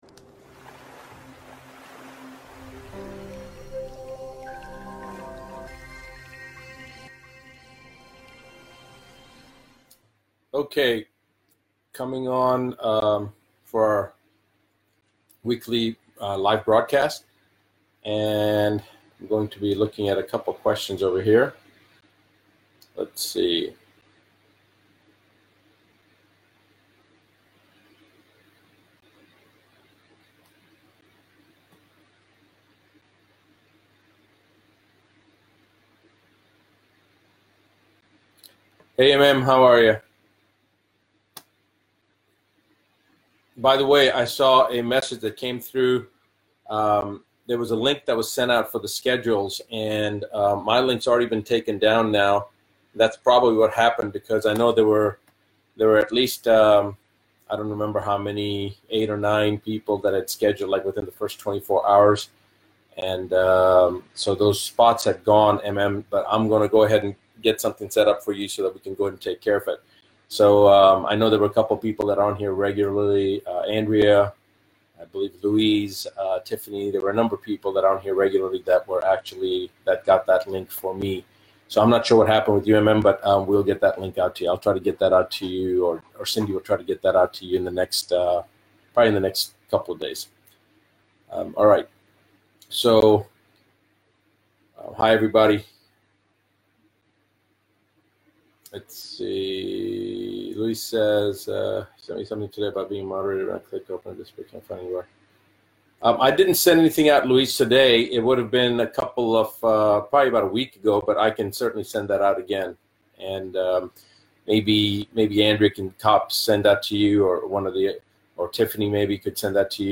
Advanced Medicine LIVE Show